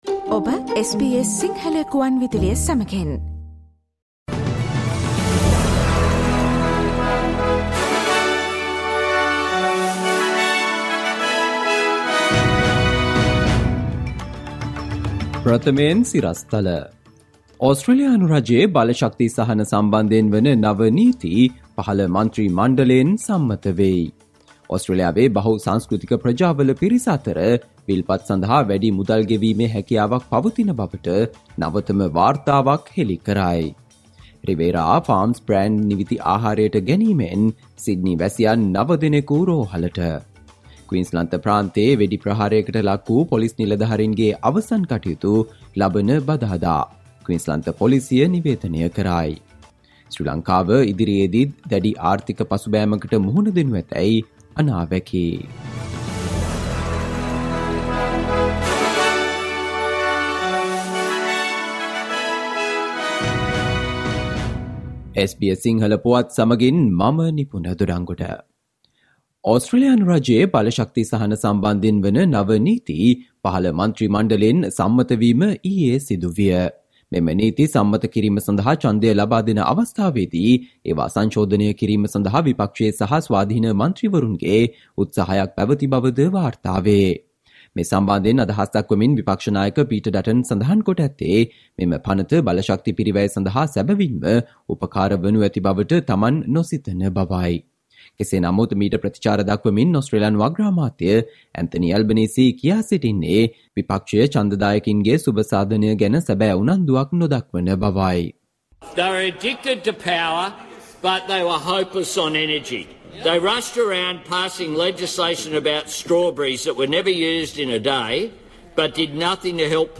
Listen to the SBS Sinhala Radio news bulletin on Friday, 16 December 2022